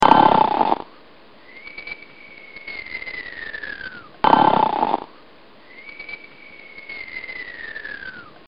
Snore Sound 4